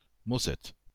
Mosset (French pronunciation: [mɔsɛt]